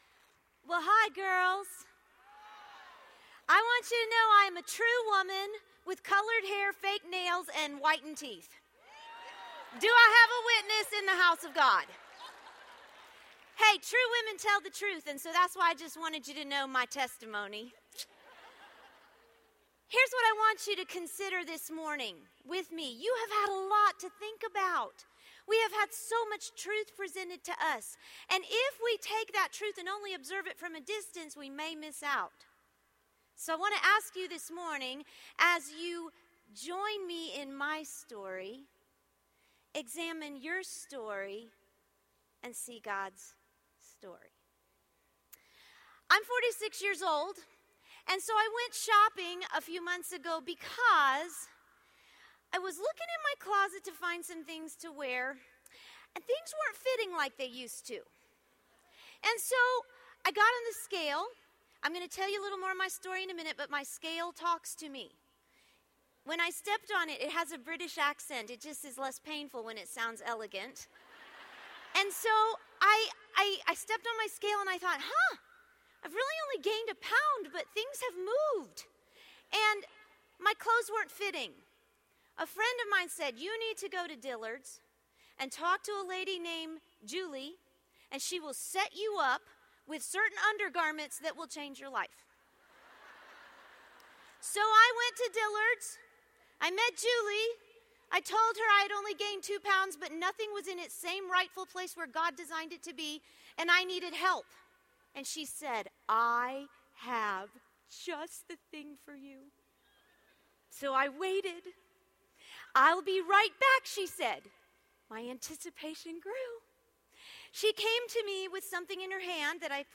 | True Woman '10 Chattanooga | Events | Revive Our Hearts